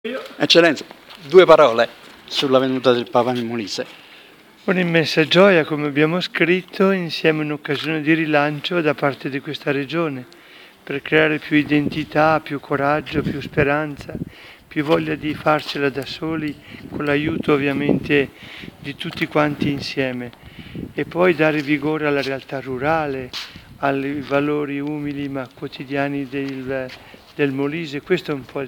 Nella Cappella della Fondazione di Ricerca e Cura “Giovanni Paolo II” si è svolto un incontro formativo sul tema “Pietro: fede e lacrime la Misericordia che riscatta”. Relatore dell’incontro S.E. Mons. GianCarlo Maria BREGANTINI, Arcivescovo di Campobasso-Bojano.